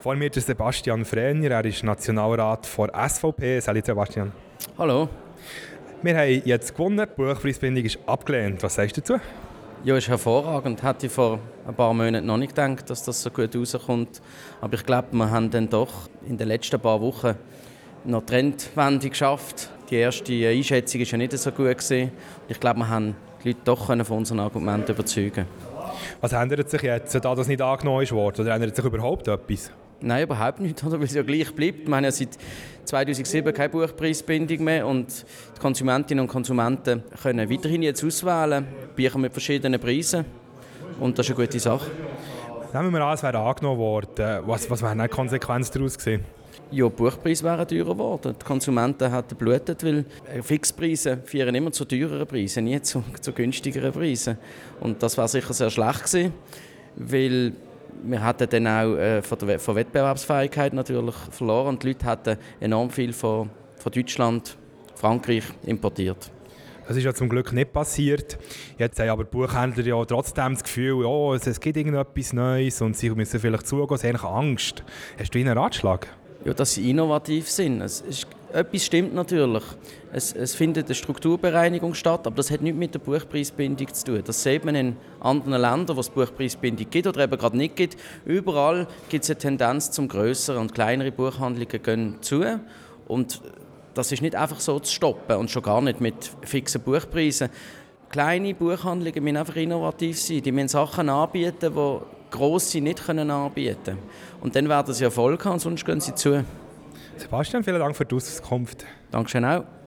Abstimmungsparty - Sebastian Frehner